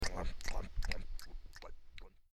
eating.mp3